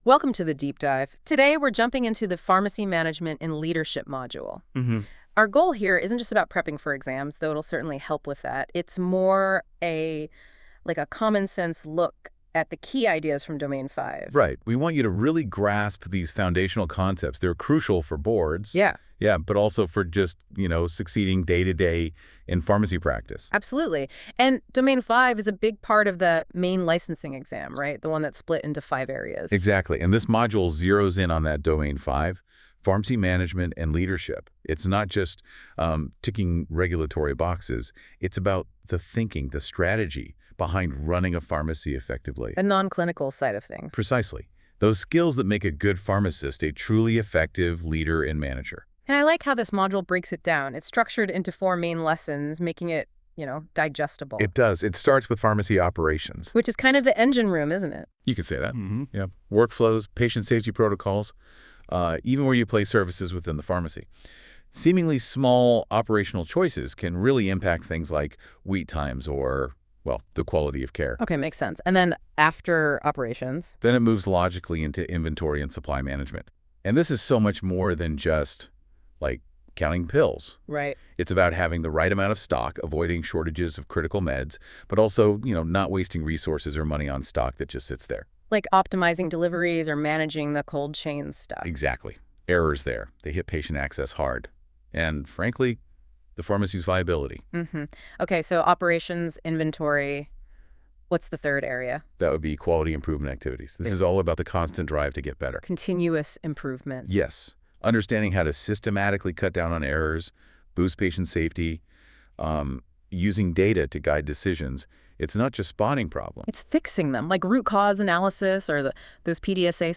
Six short podcast episodes (approximately 1 hour and 30 minutes in total), generated by AI from the study guide, are designed to reinforce key concepts through active listening.